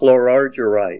Help on Name Pronunciation: Name Pronunciation: Chlorargyrite + Pronunciation